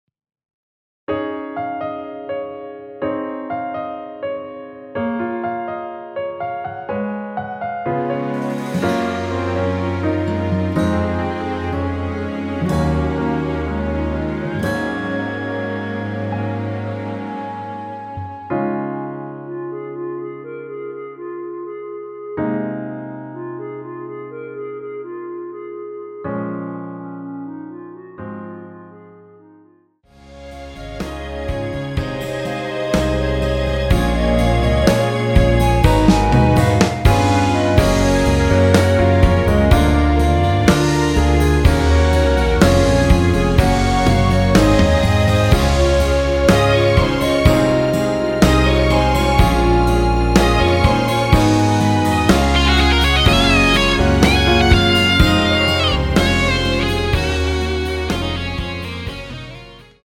원키 멜로디 포함된 MR입니다.(미리듣기 확인)
Db
앞부분30초, 뒷부분30초씩 편집해서 올려 드리고 있습니다.